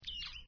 snd_mouse.ogg